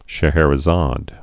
(shə-hĕrə-zäd)